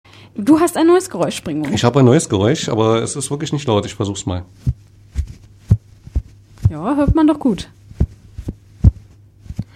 Geräusch! - ratet und gewinnt!!
geraeusch2.mp3